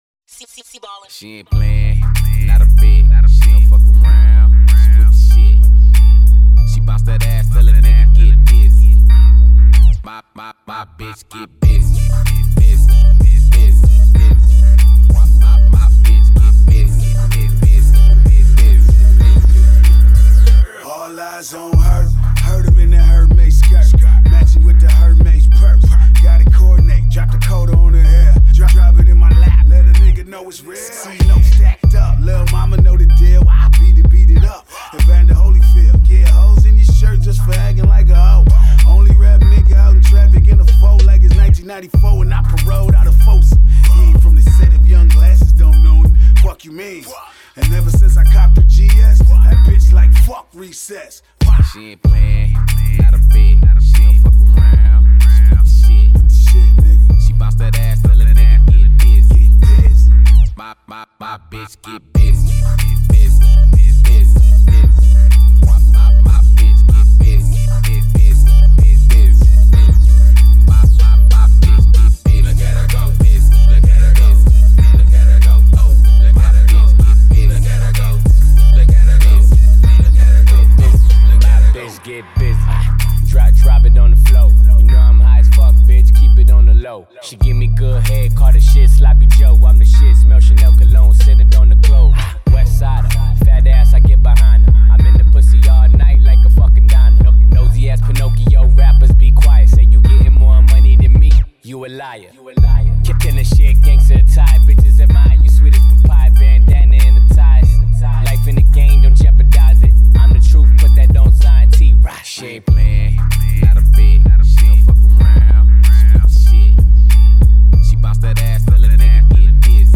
bass____rap.mp3